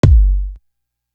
Live On Stage Kick.wav